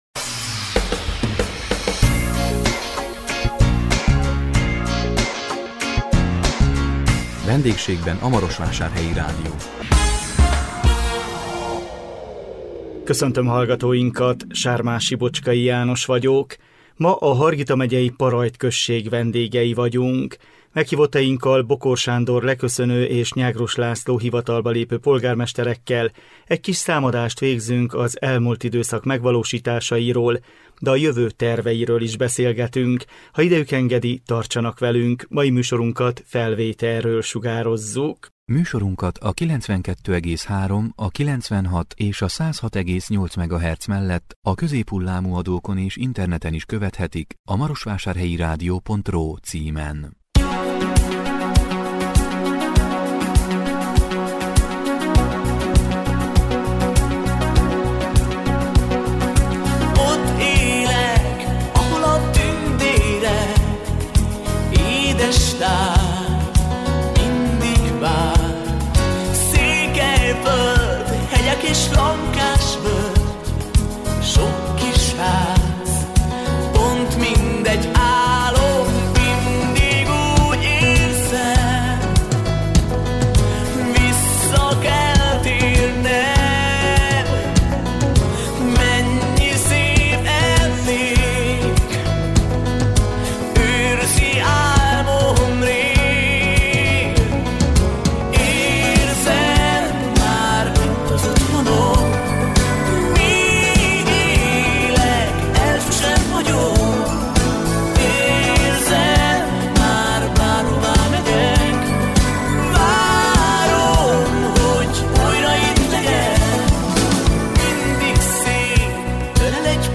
A 2020 október 15-én jelentkező VENDÉGSÉGBEN A MAROSVÁSÁRHELYI RÁDIÓ című műsorunkban a Hargita megyei Parajd község vendégei voltunk. Meghívottainkkal, Bokor Sándor leköszönő- és Nyágrus László hivatalba lépő polgármesterekkel egy kis számadást végeztünk az elmúlt időszak megvalósításairól, de a jövő terveiről is beszélgettünk.